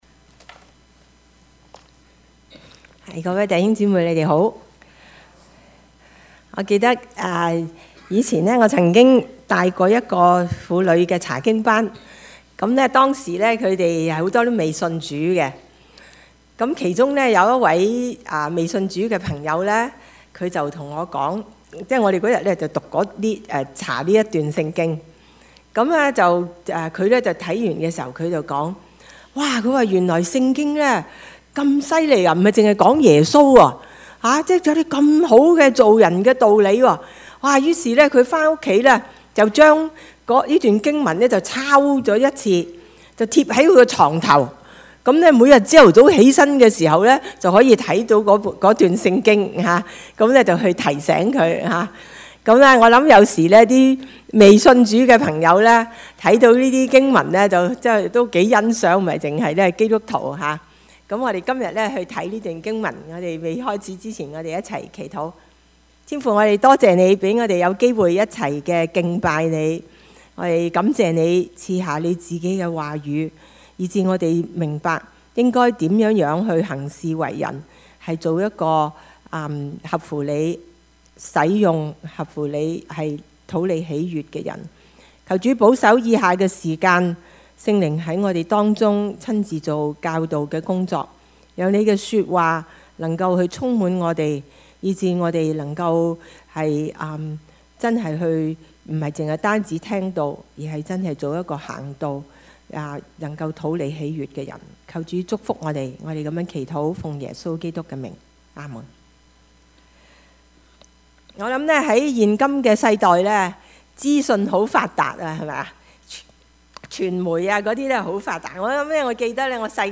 Passage: 以弗所書 4:25-32 Service Type: 主日崇拜
Topics: 主日證道 « 你不要懼怕！